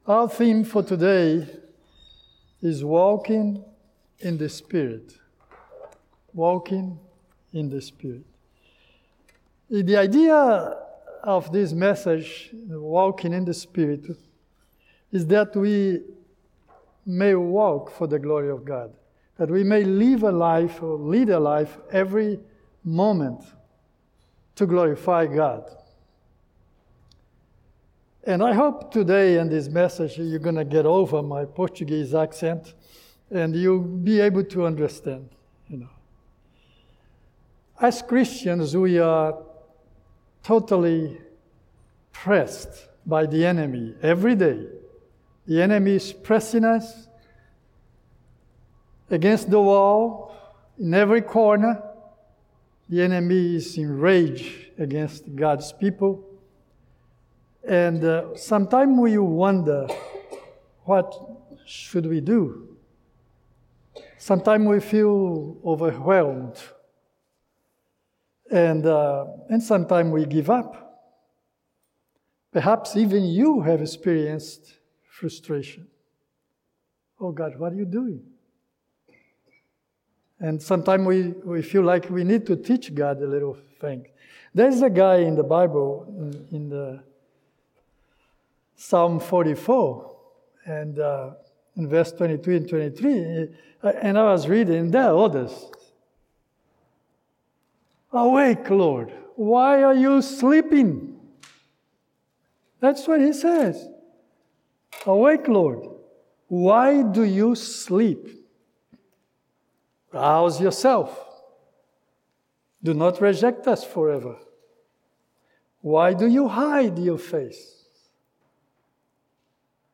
Good Friday service. 2024